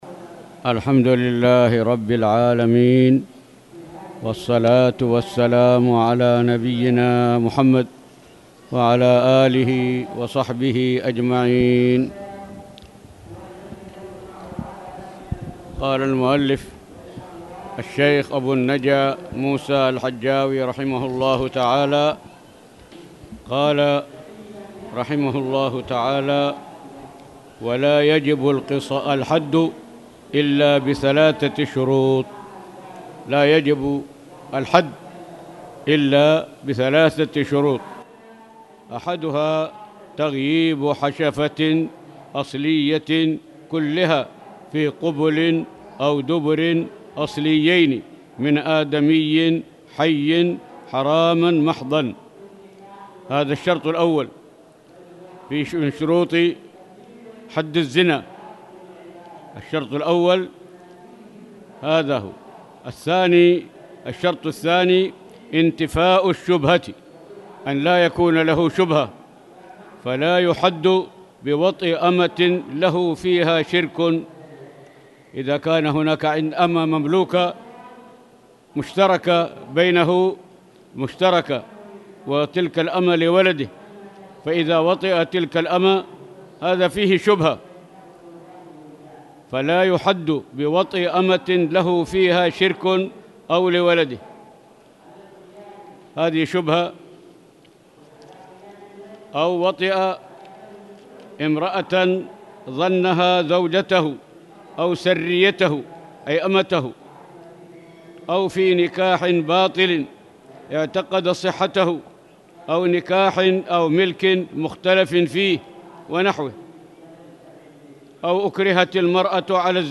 تاريخ النشر ٨ صفر ١٤٣٨ هـ المكان: المسجد الحرام الشيخ